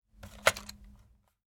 Descarga de Sonidos mp3 Gratis: telefono 9.